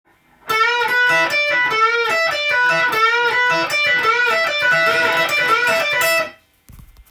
譜面通り弾いてみました